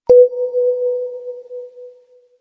back-button-click.wav